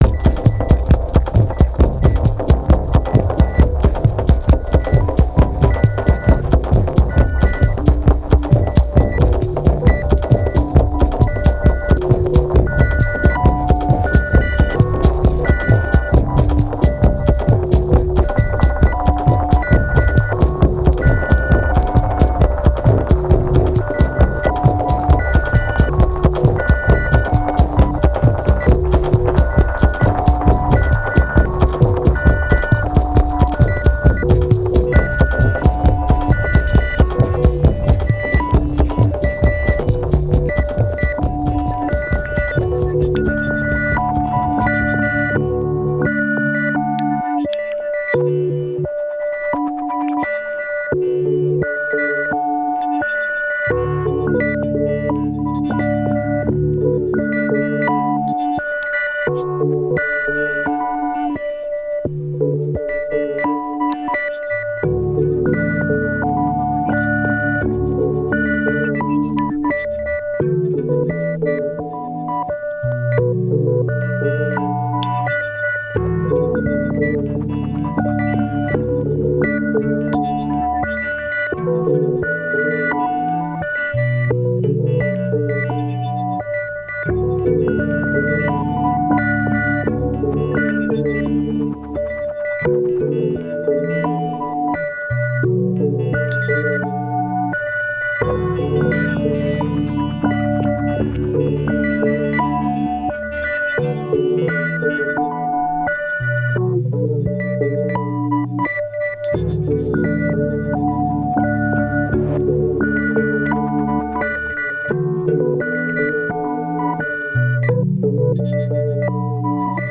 a DJ mix by our current executive director
Electronic Experimental Indie